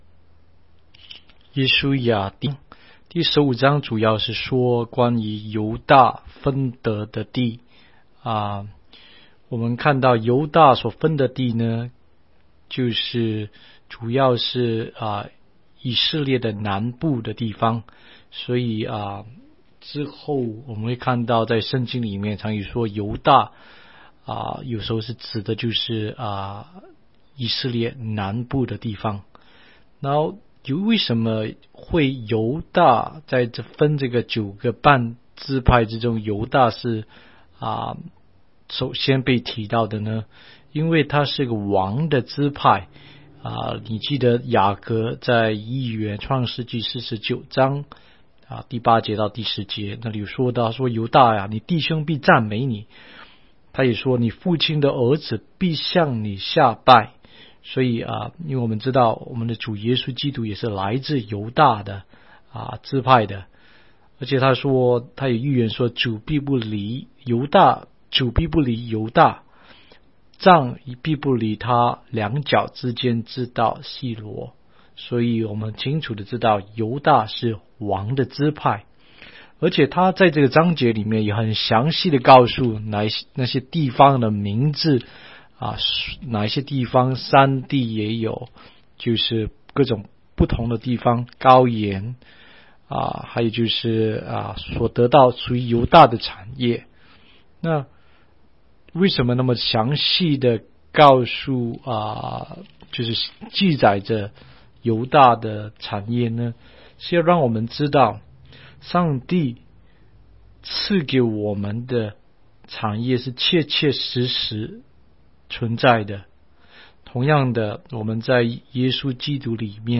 16街讲道录音 - 每日读经-《约书亚记》15章